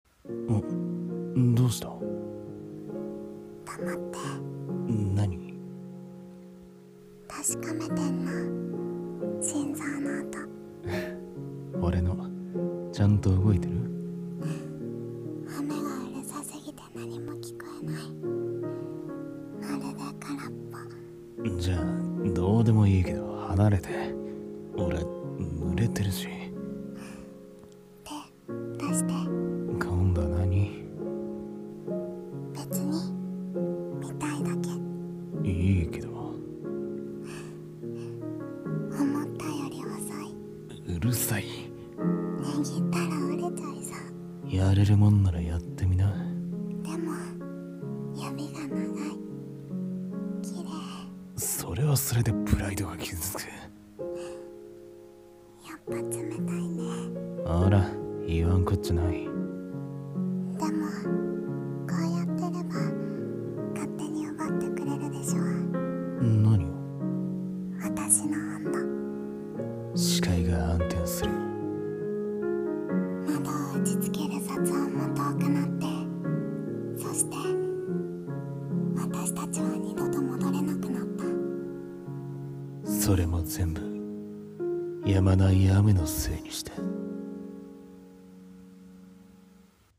声劇【ノイズ】